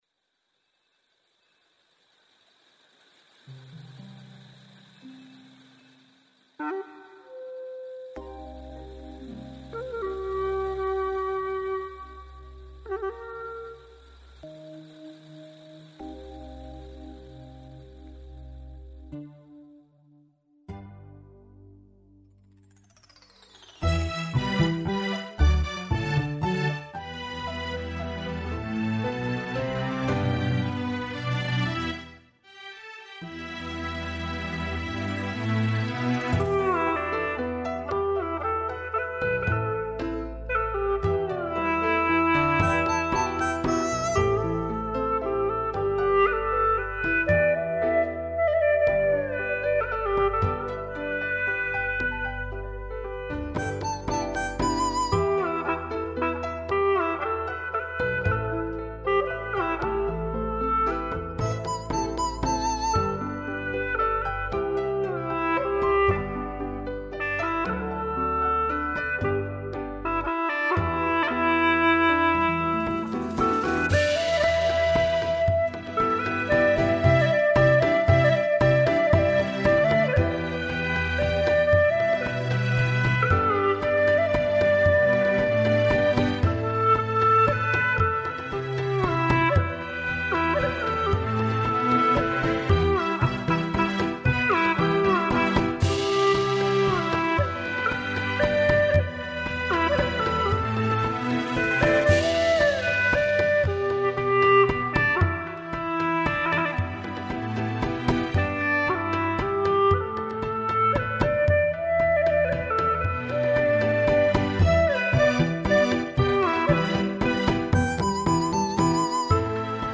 调式 : G 曲类 : 民族